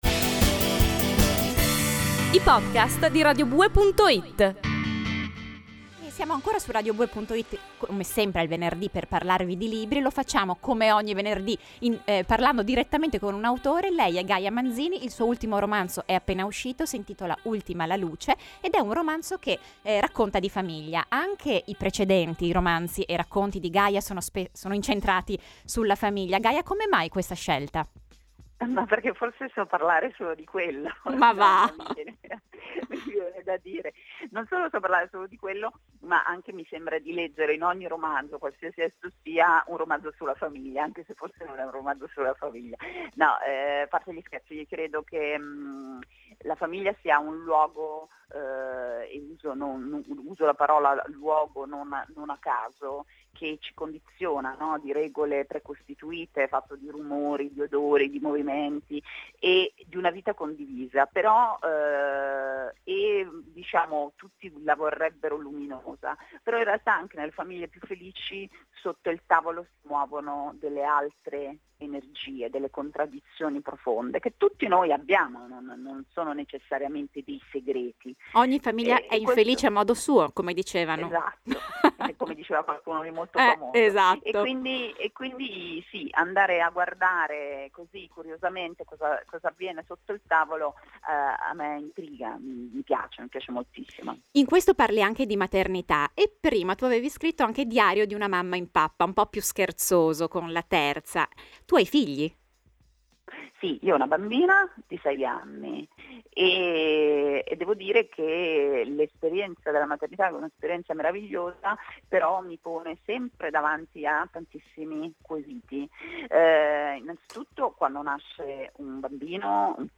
Ascolta la seconda parte dell’intervista (o scaricala qui) e scopri cosa lei ci racconta di tutto questo e, anche, se ha già il pensiero su un nuovo progetto: